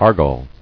[ar·gol]